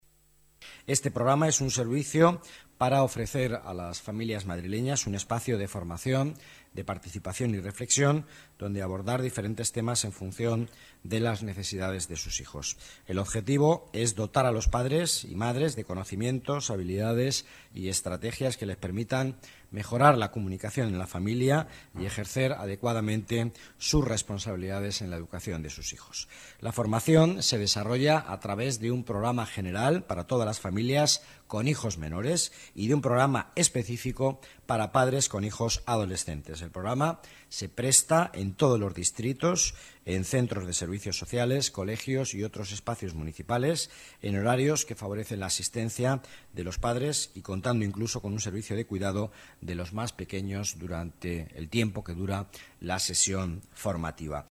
Nueva ventana:Declaraciones del vicealcalde de Madrid, Miguel Ángel Villanueva